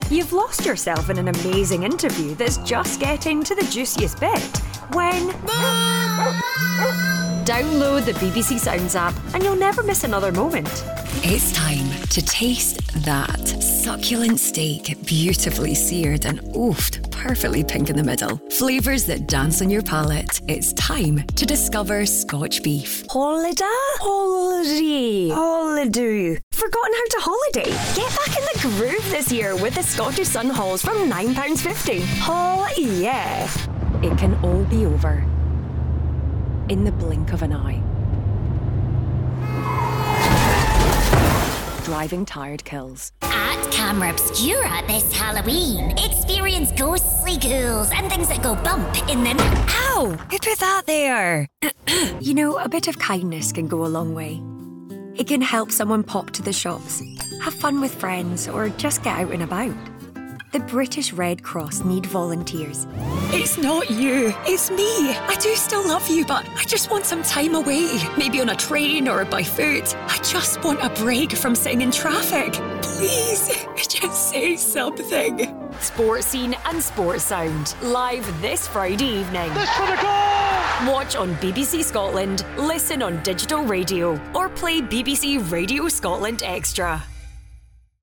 Inglés (escocés)
Soy un locutor escocés a tiempo completo que trabaja en un estudio de calidad profesional.
Susurrante
Cálido
Amistoso